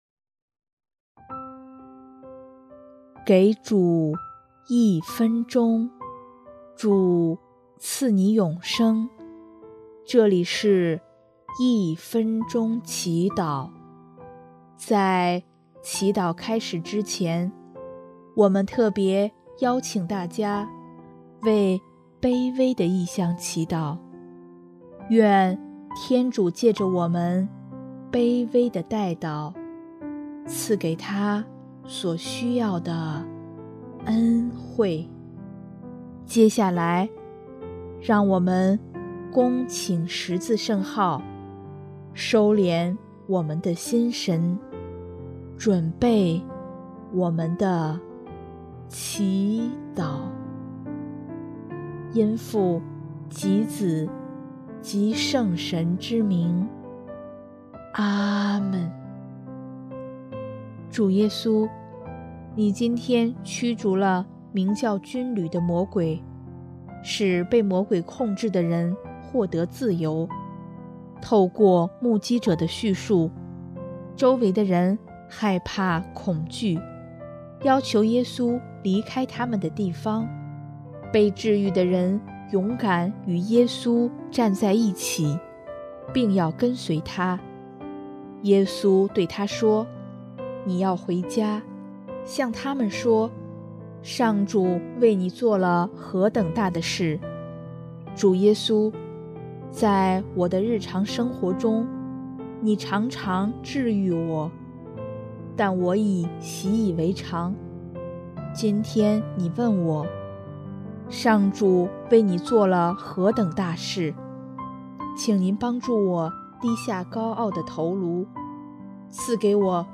【一分钟祈祷】|2月3日 上主为你做了何等大事
音乐： 第四届华语圣歌大赛参赛歌曲《治愈》